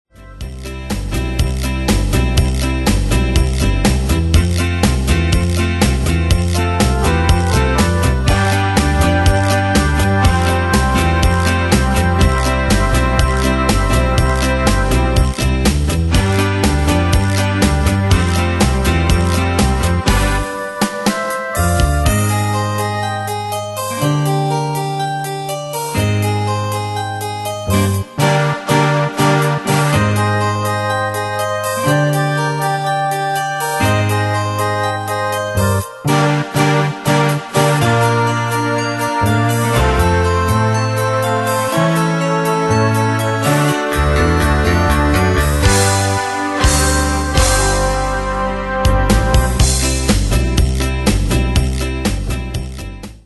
Demos Midi Audio
Danse/Dance: Continental Cat Id.